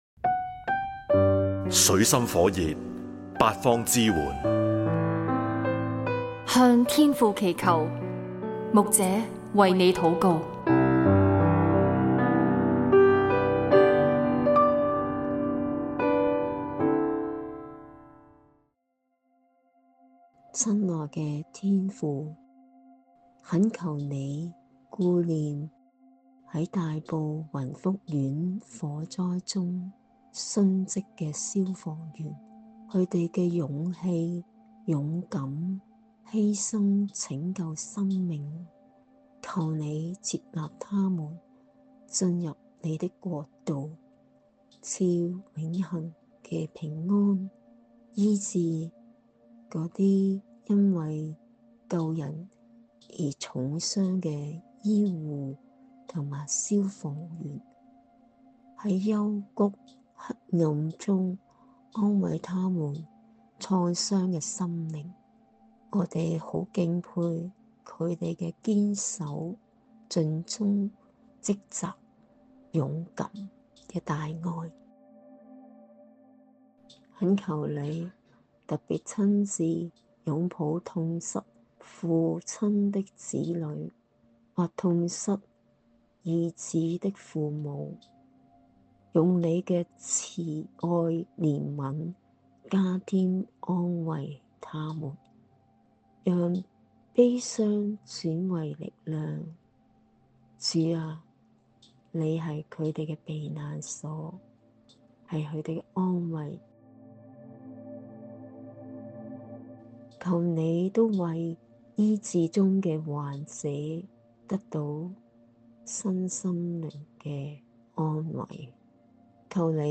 [大埔宏福苑火災] 牧者為你禱告